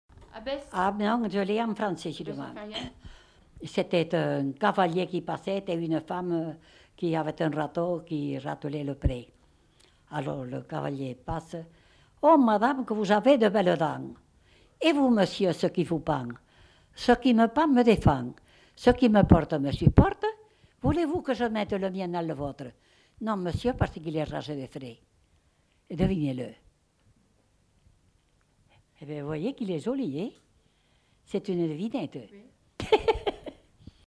Devinette
Aire culturelle : Haut-Agenais
Genre : forme brève
Effectif : 1
Type de voix : voix de femme
Production du son : récité
Classification : devinette-énigme